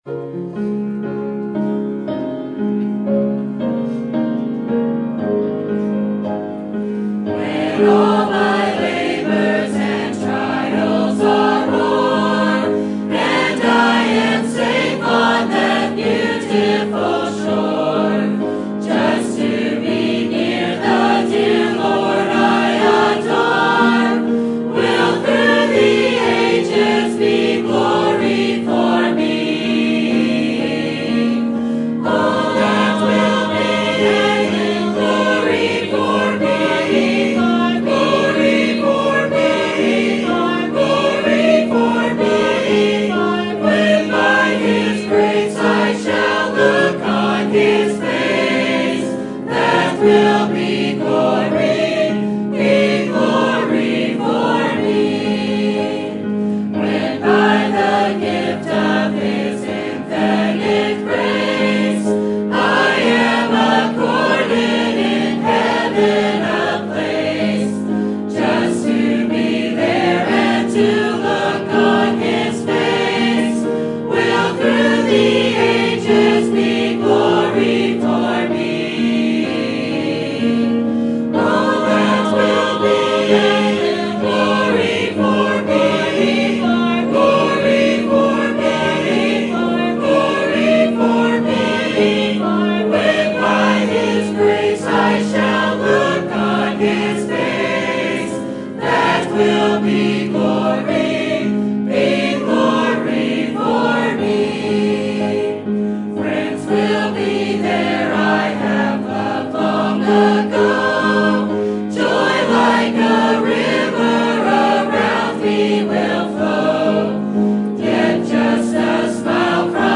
Sermon Topic: General Sermon Type: Service Sermon Audio: Sermon download: Download (28.82 MB) Sermon Tags: 1 Samuel David Nabal Listening